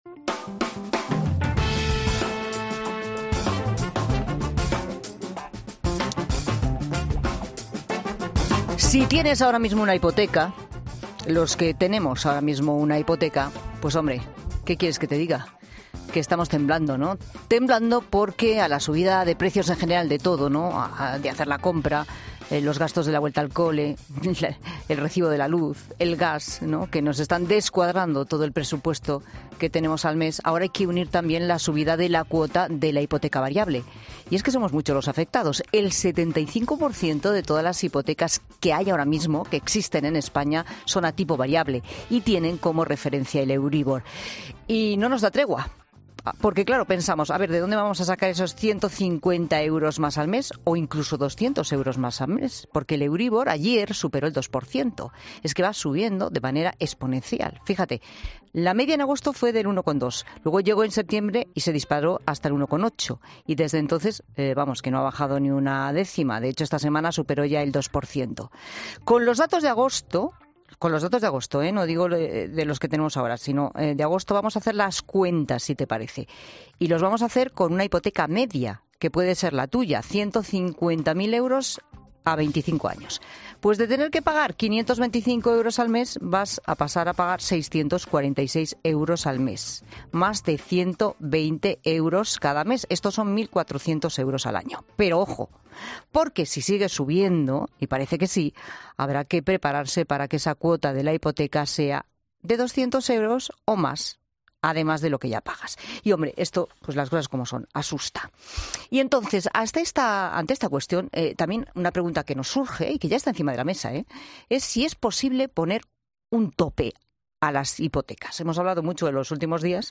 El profesor de economía de bolsillo de 'La tarde', Fernando Trías de Bes, ha explicado a la codirectora del programa, Pilar Cisneros, las implicaciones...